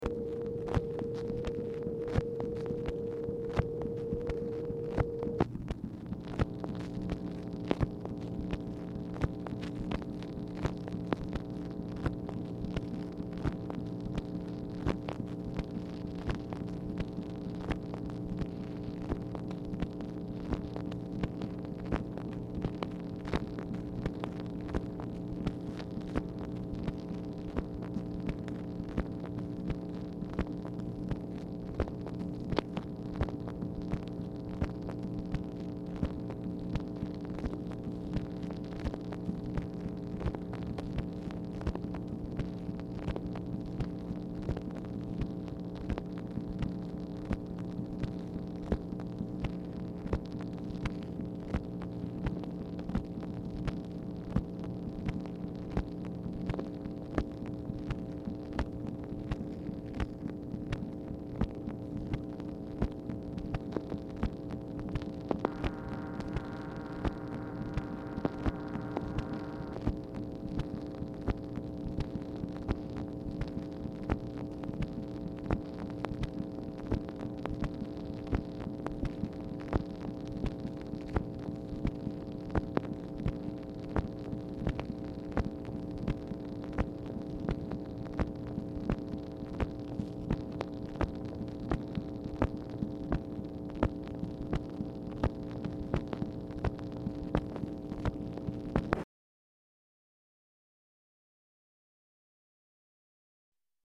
Telephone conversation # 7839, sound recording, MACHINE NOISE, 5/27/1965, time unknown | Discover LBJ
Format Dictation belt
Specific Item Type Telephone conversation